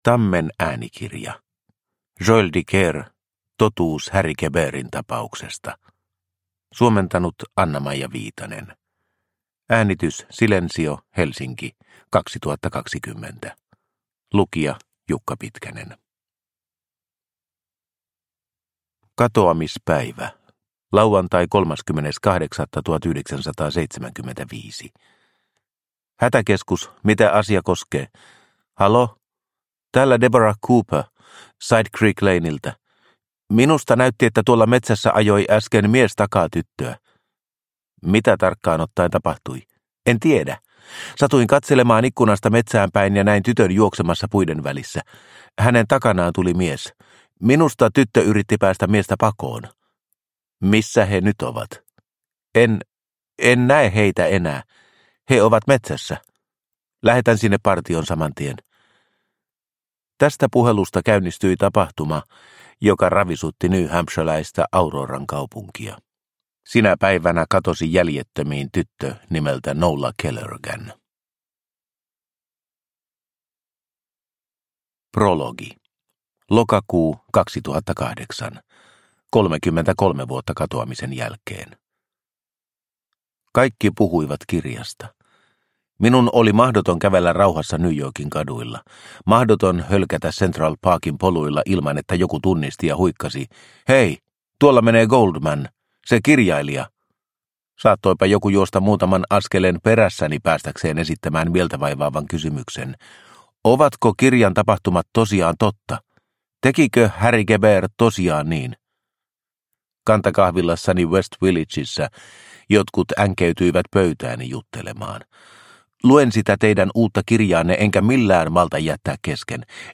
Totuus Harry Quebertin tapauksesta – Ljudbok – Laddas ner